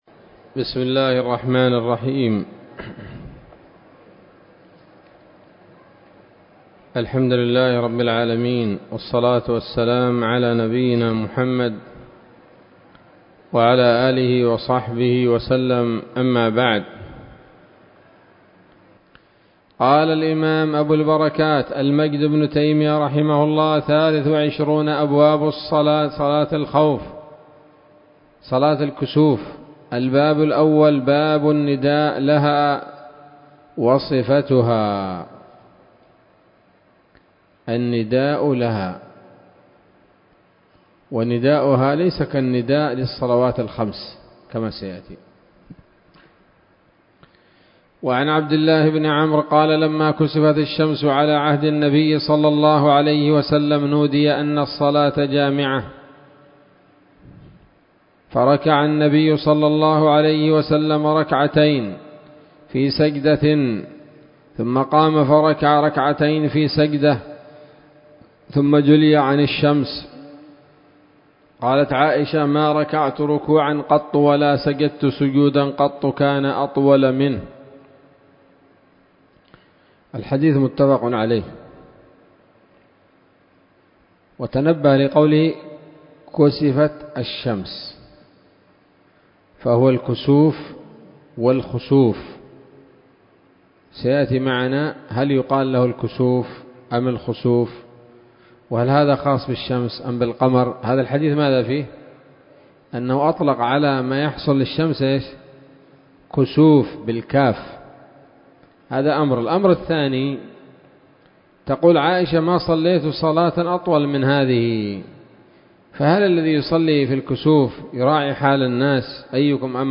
الدرس الأول من ‌‌‌‌كتاب صلاة الكسوف من نيل الأوطار